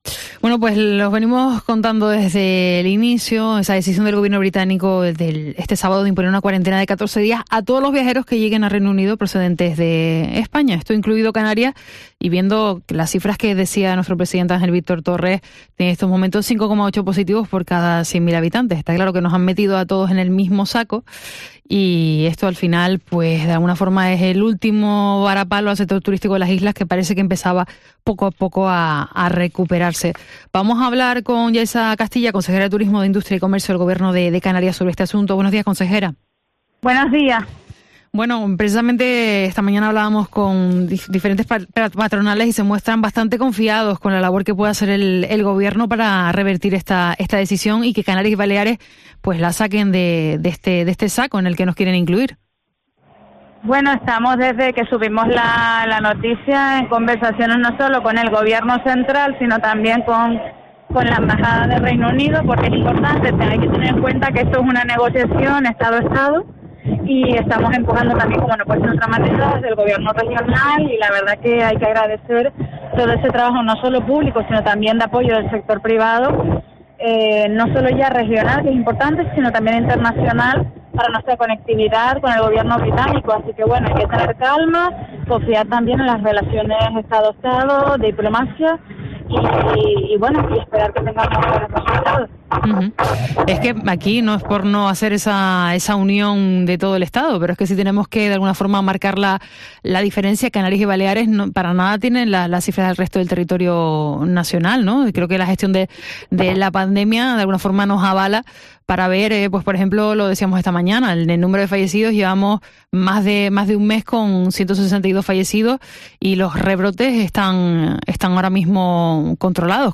AUDIO: Entrevista a Yaiza Castilla en COPE Canarias